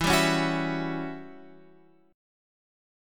E Minor 6th Add 9th